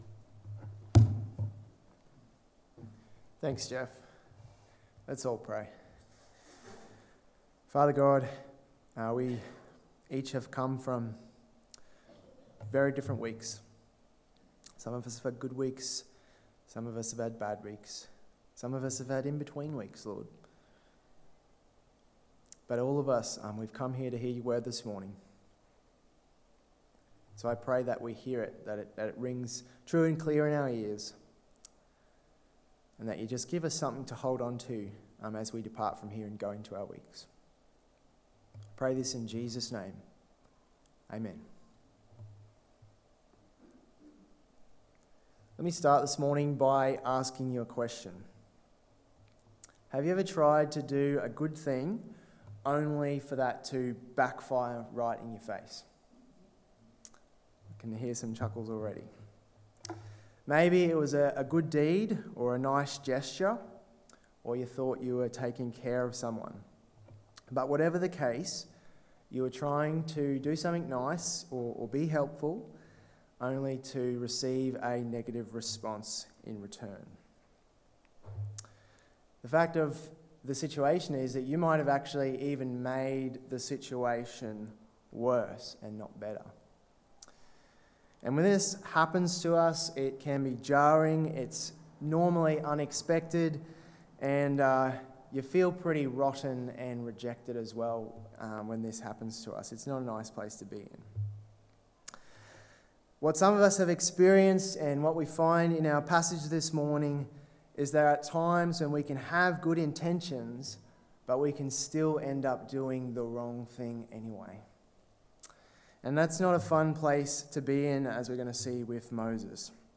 Moses a Fugitive (Exodus 2:11-25 Sermon) 14/05/2023